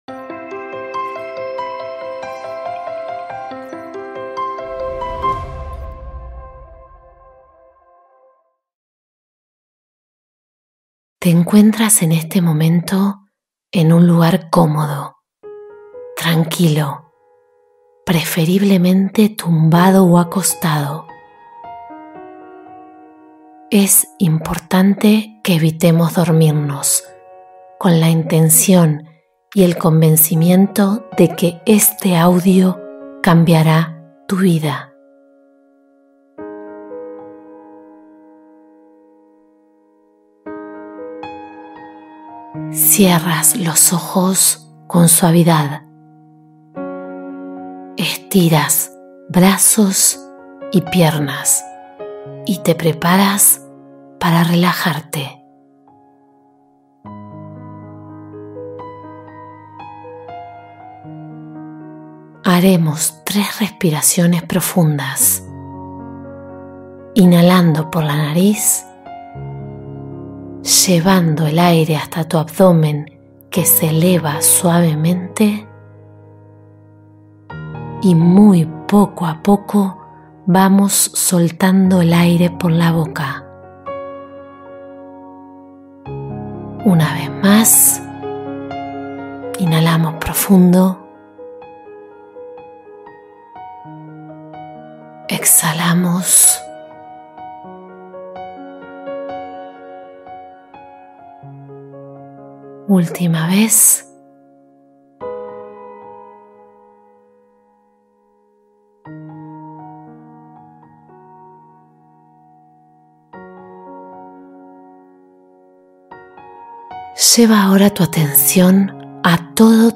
Acepta tu ser con esta meditación mindfulness para amarte tal como eres